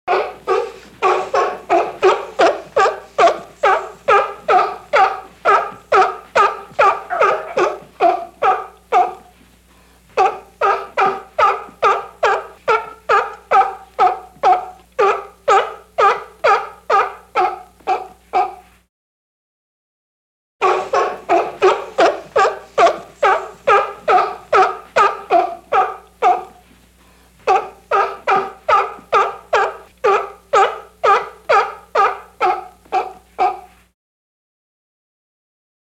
جلوه های صوتی
دانلود صدای حیوان آبی 3 از ساعد نیوز با لینک مستقیم و کیفیت بالا